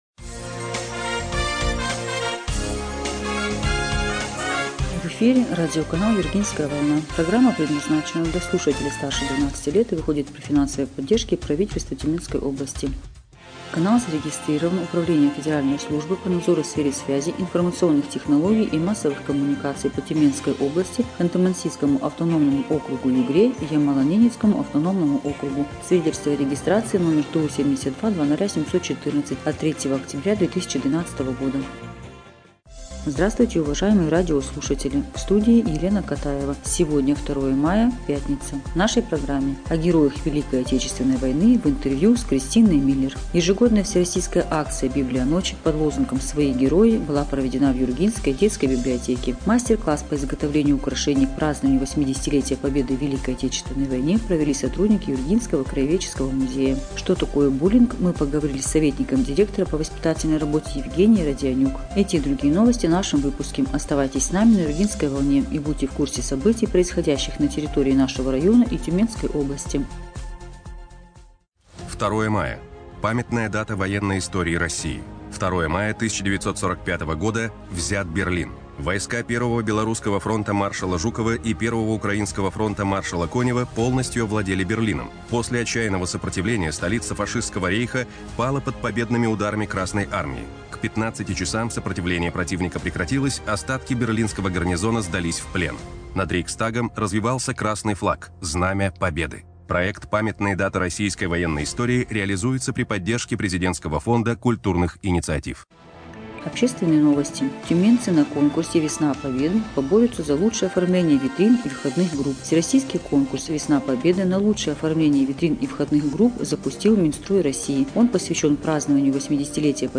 Эфир радиопрограммы "Юргинская волна" от 2 мая 2025 года